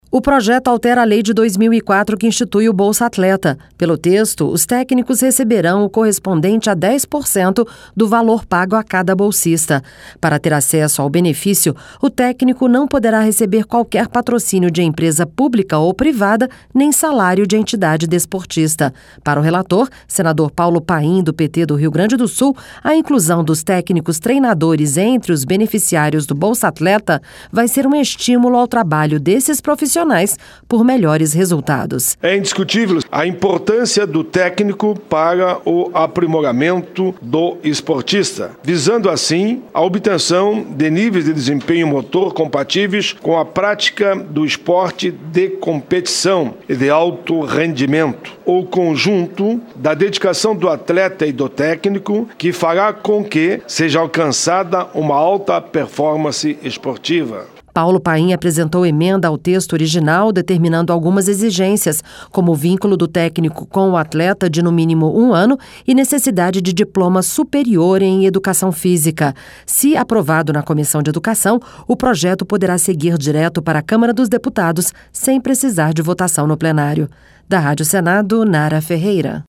(Repórter) O projeto altera a lei de 2004 que institui o Bolsa Atleta.
Para o relator, senador Paulo Paim, do PT do Rio Grande do Sul, a inclusão dos técnicos treinadores entre os beneficiários do Bolsa-Atleta vai ser um estímulo ao trabalho desses profissionais por melhores resultados.